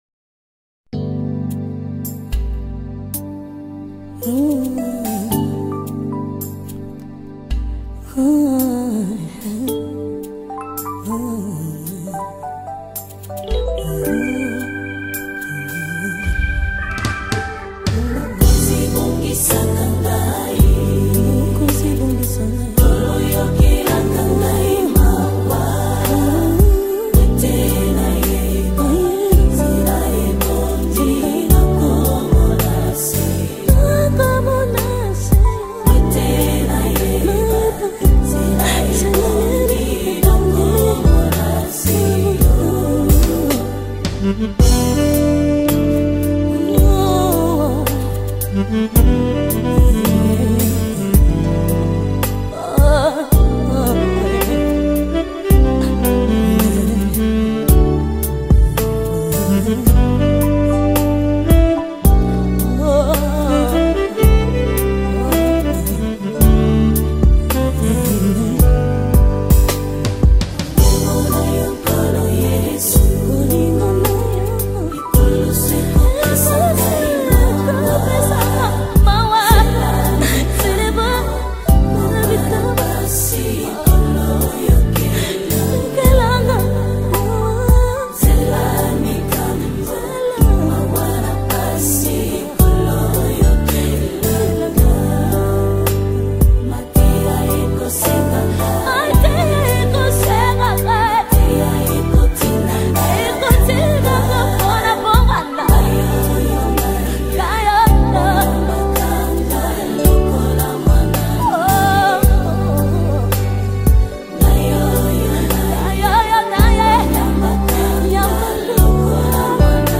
Congo Gospel Music